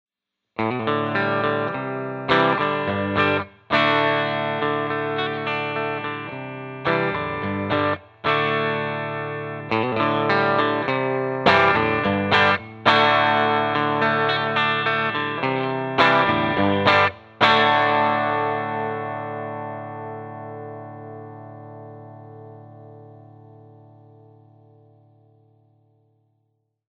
55T (1955 Tele lead type) alone
55T  bridge.mp3